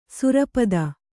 ♪ surapada